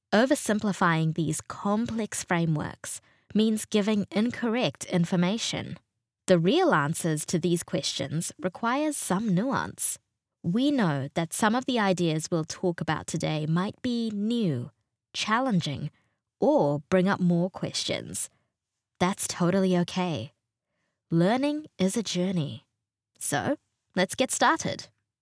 new zealand | natural